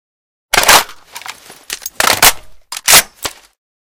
reload_empty1.ogg